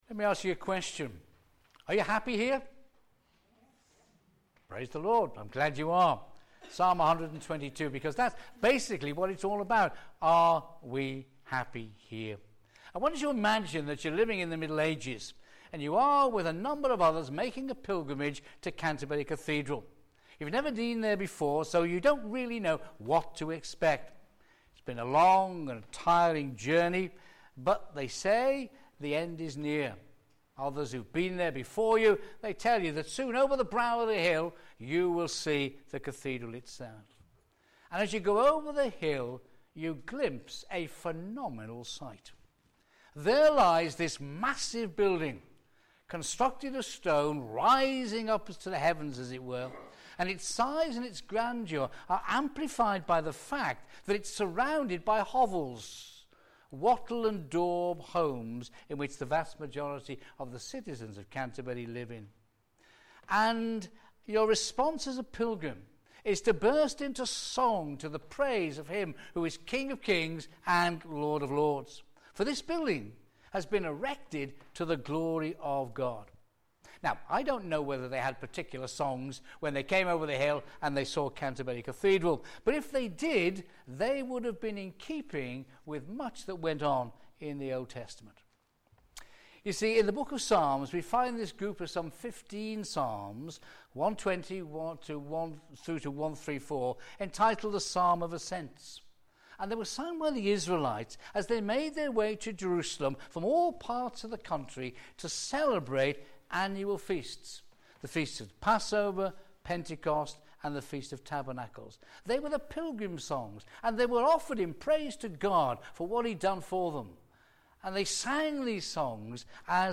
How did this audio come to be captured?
a.m. Service